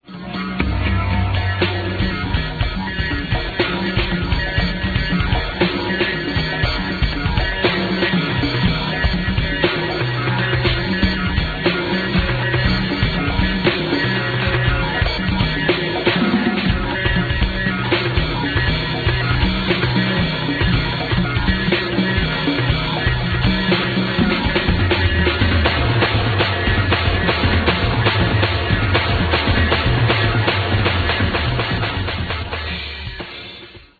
was performed live for the Television show